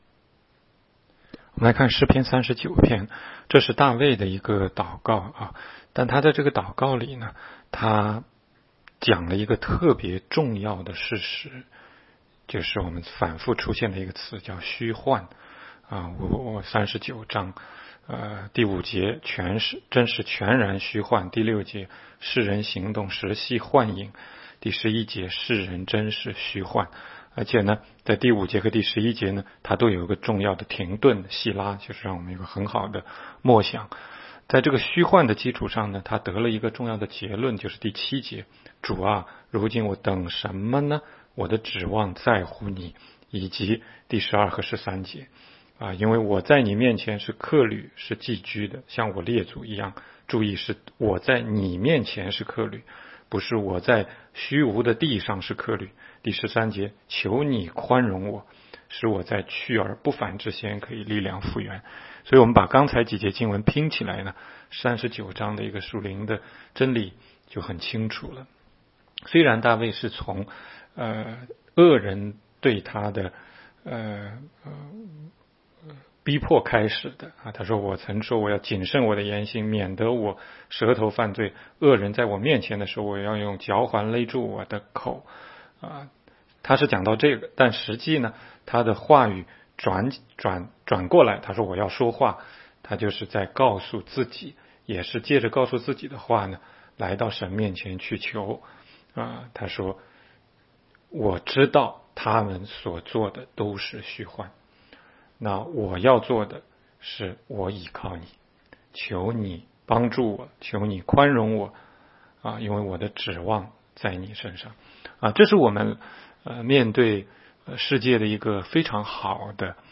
16街讲道录音 - 每日读经-《诗篇》39章